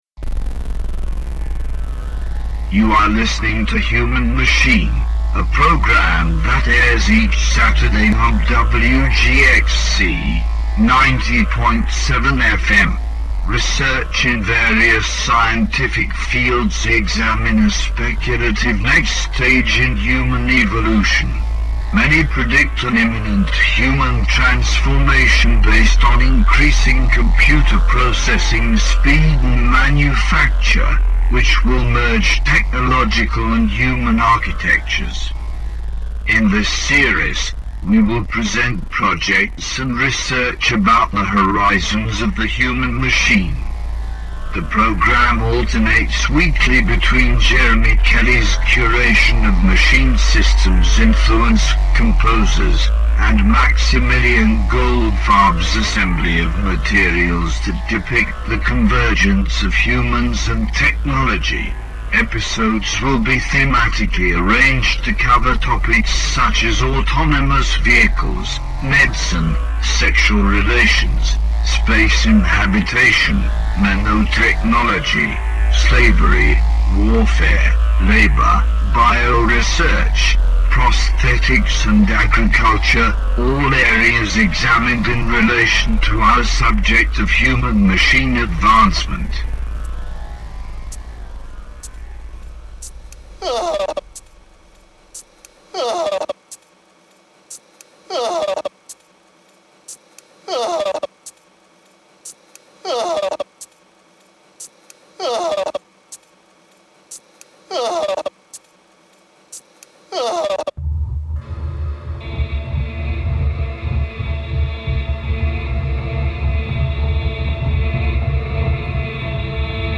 Human Machine is a hour-long radio broadcast produ...